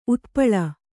♪ utpaḷa